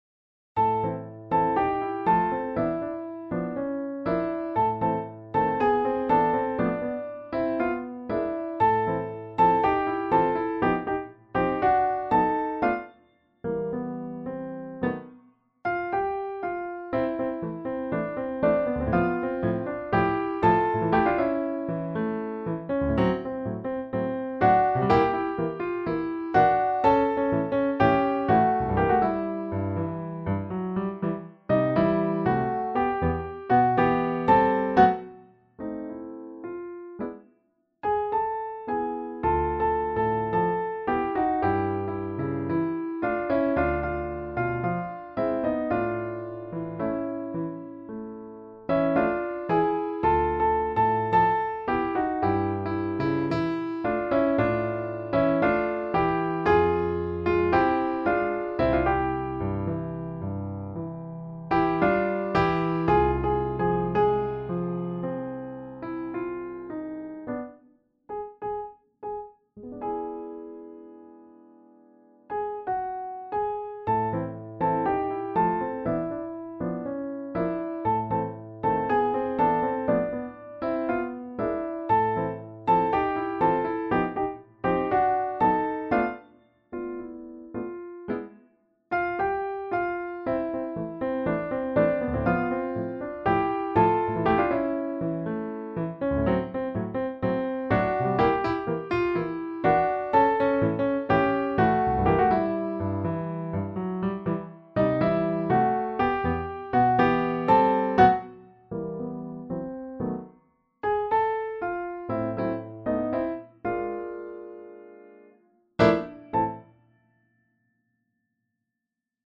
Piano duet 1st part easy